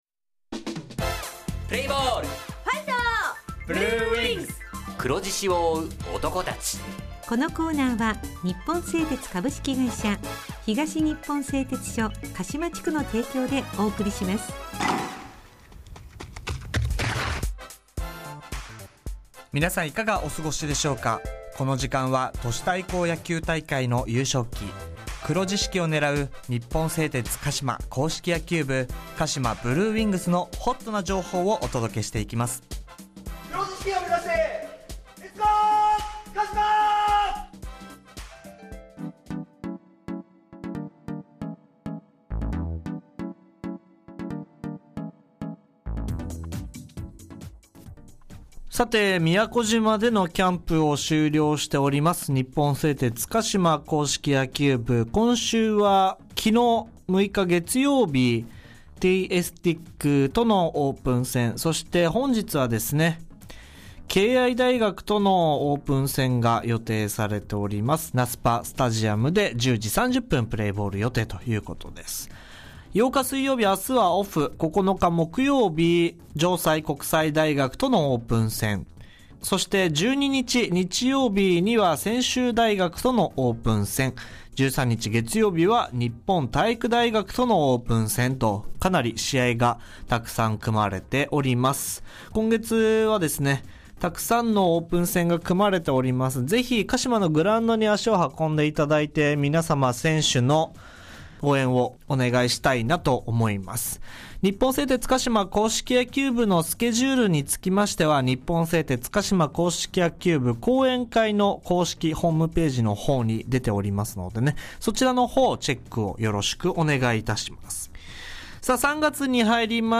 地元ＦＭ放送局「エフエムかしま」にて当所硬式野球部の番組放送しています。